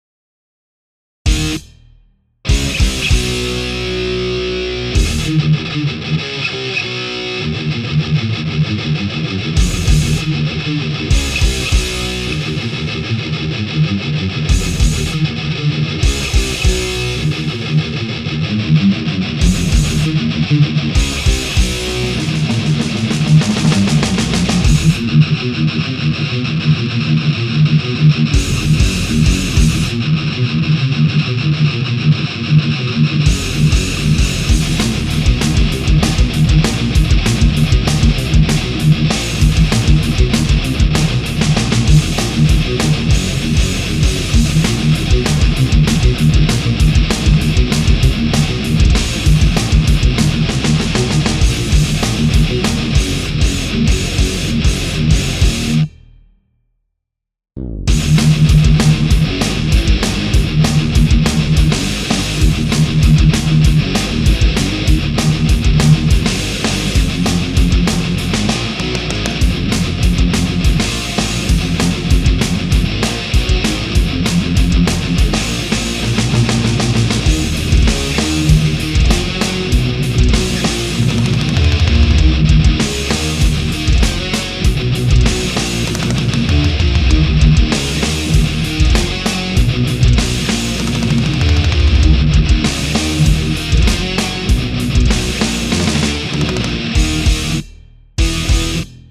��� ���� ������� ���������� � 195bpm!!!